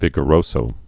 (vĭgə-rōsō, -zō, vēgə-)